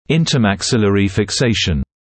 [ˌɪntəmæk’sɪlərɪ fɪk’seɪʃn][ˌинтэмэк’силэри фик’сэйшн]межчелюстная фиксация (например по время хирургическое операции)